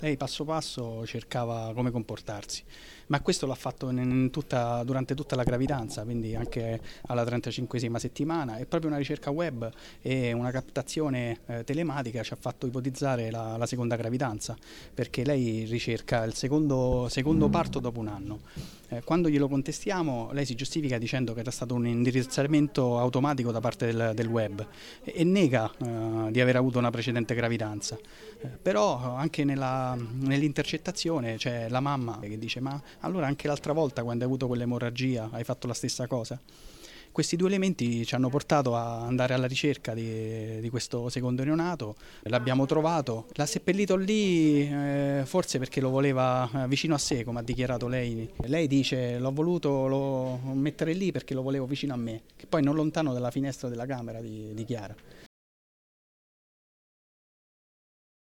È quanto è emerso nel corso di una conferenza stampa tenuta dal Procuratore di Parma, Alfonso D’Avino, nella mattinata di oggi, venerdì 20 settembre, nella città Ducale.
Il Comandante Provinciale dei Carabinieri di Parma, Andrea Pagliaro , spiega come la  principale preoccupazione della 21enne pare fosse quella di nascondere la gravidanza come testimoniato dalle cronologie delle ricerche sul web